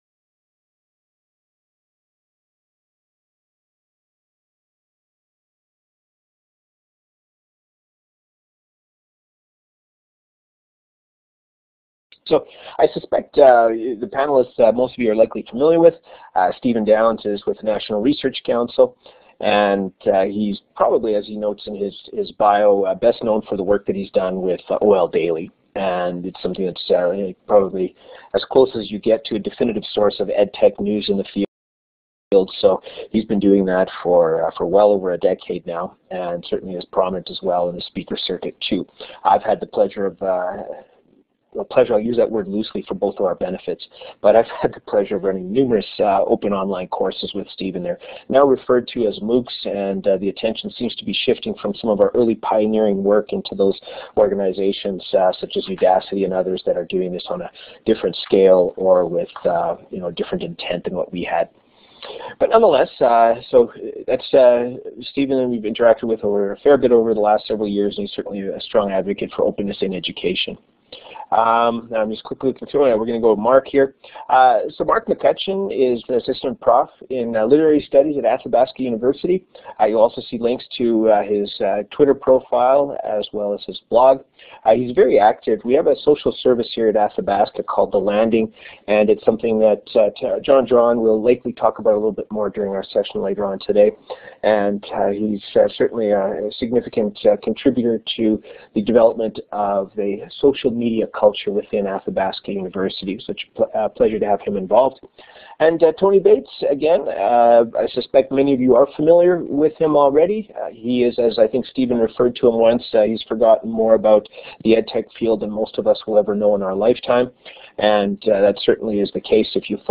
Online, via Blackboard Collaborate, Panel